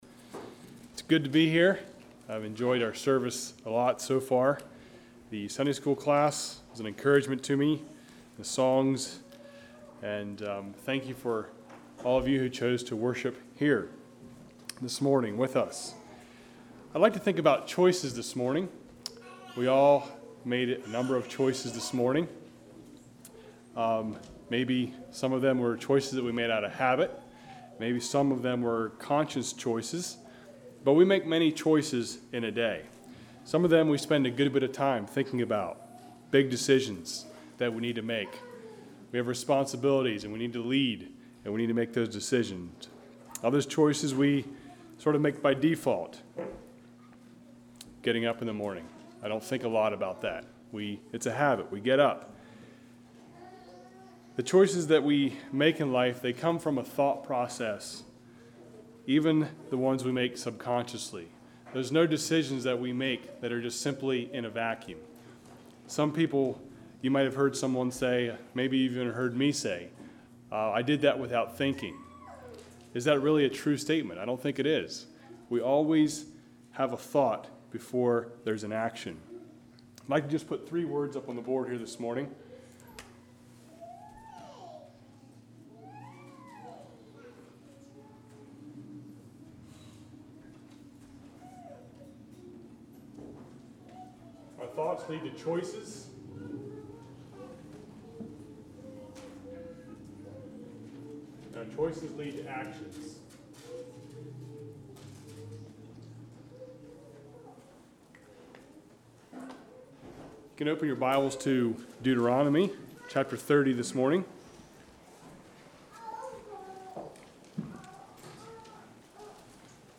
Congregation: Fourth Avenue